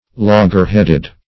loggerheaded - definition of loggerheaded - synonyms, pronunciation, spelling from Free Dictionary
Loggerheaded \Log"ger*head`ed\, a.